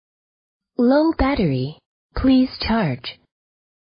low_power.mp3